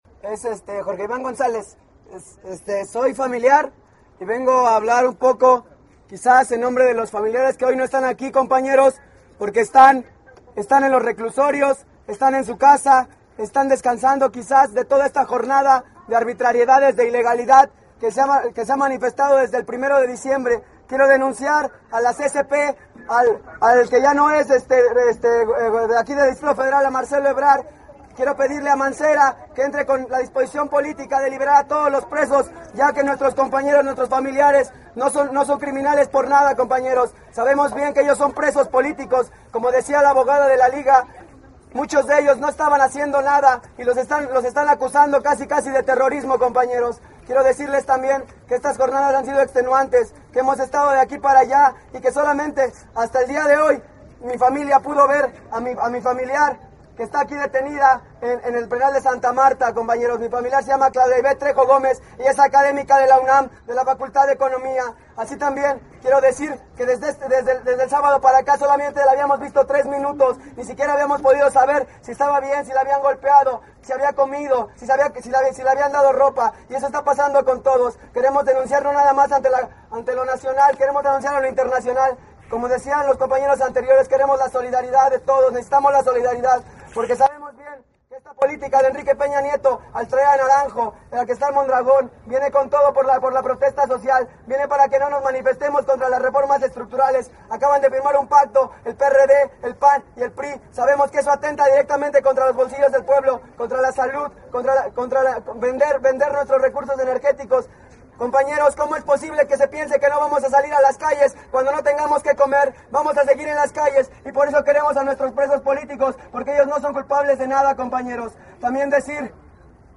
Concentración en el Penal de Santa Martha Acatitla por la liberación de las 11 presas políticas
04Participacion_familiar.mp3